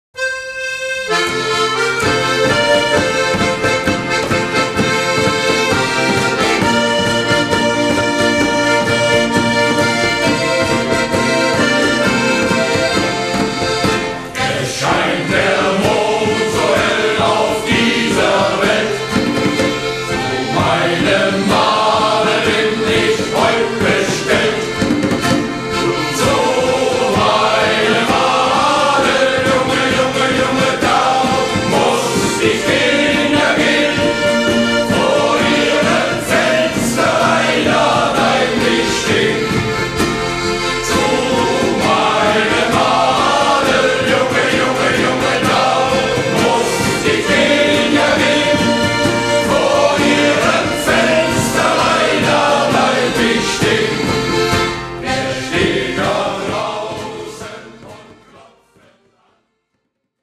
Our Shanty-Chor